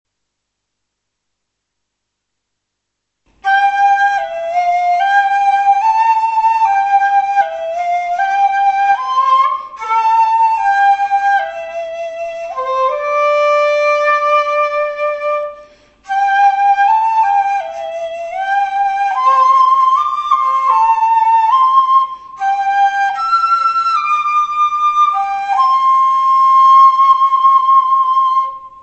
尺八練習曲
春が来た　ok １コーラス（高い吹き方）